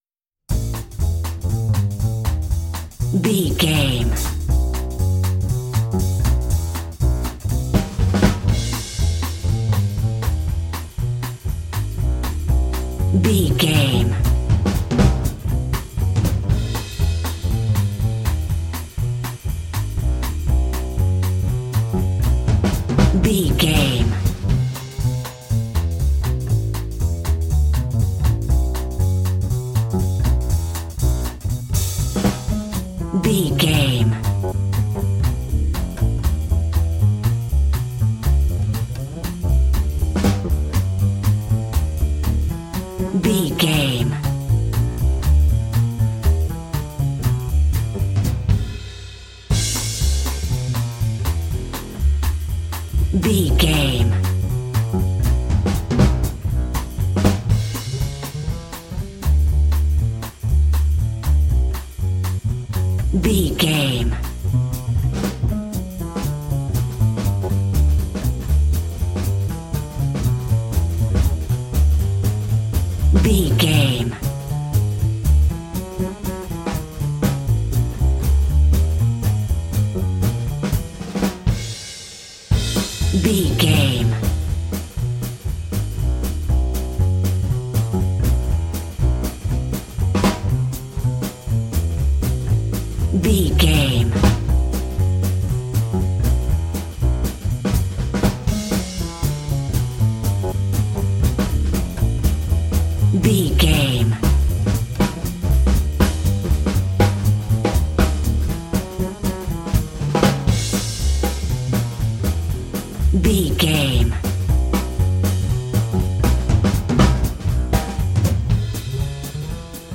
Ionian/Major
Fast
energetic
driving
groovy
drums
double bass
bebop swing
jazz